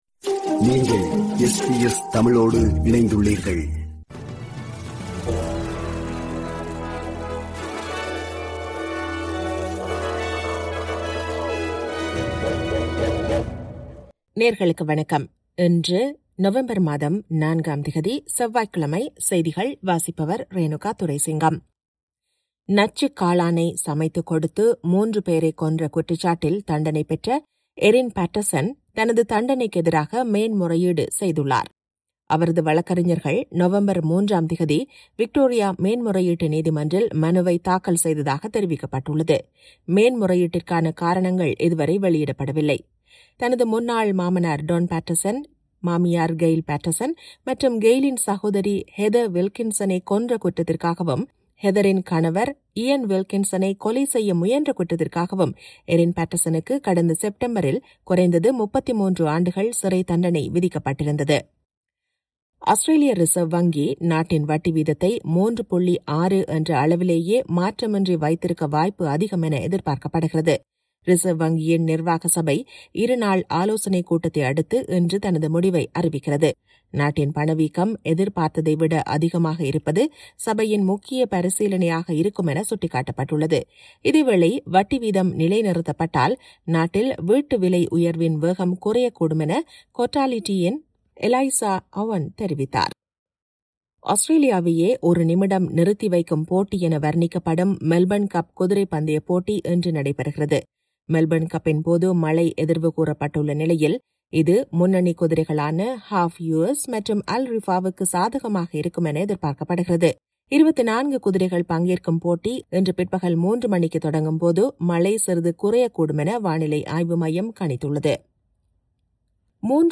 இன்றைய செய்திகள்: 04 நவம்பர் 2025 செவ்வாய்க்கிழமை
SBS தமிழ் ஒலிபரப்பின் இன்றைய (செவ்வாய்க்கிழமை 04/11/2025) செய்திகள்.